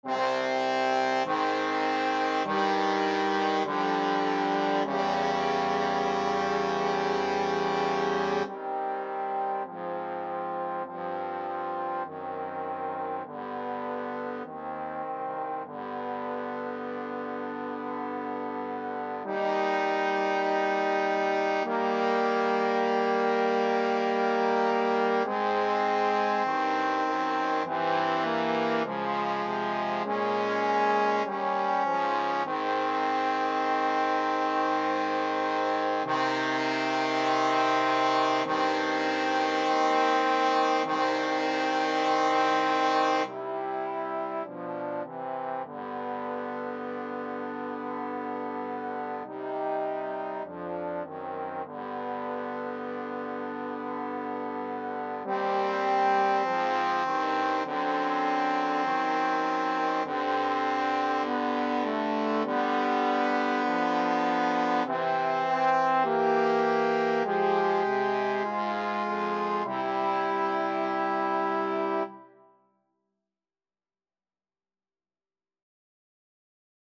Trombone 1Trombone 2Bass Trombone
Eb major (Sounding Pitch) (View more Eb major Music for Trombone Trio )
Trombone Trio  (View more Easy Trombone Trio Music)
Classical (View more Classical Trombone Trio Music)